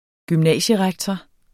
Udtale [ gymˈnæˀɕəˌʁagtʌ ]